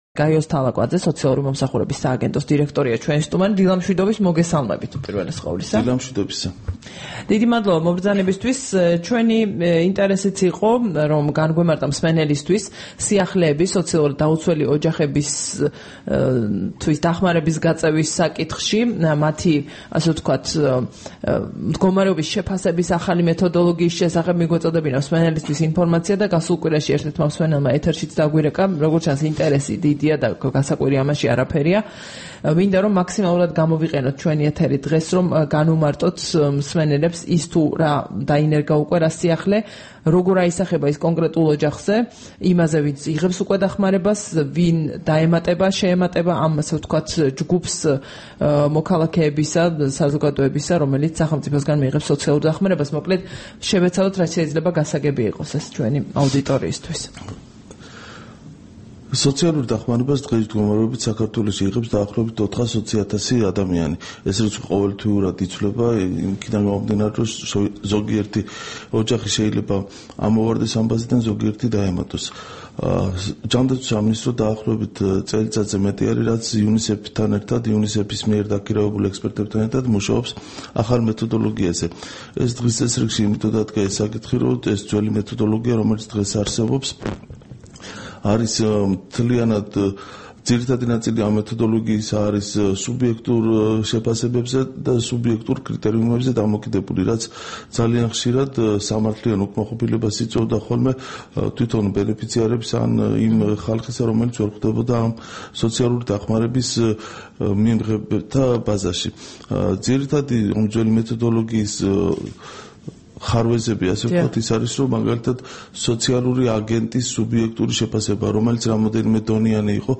8 დეკემბერს რადიო თავისუფლების დილის გადაცემის სტუმარი იყო გაიოზ თალაკვაძე, სოციალური მომსახურების სააგენტოს დირექტორი.
საუბარი გაიოზ თალაკვაძესთან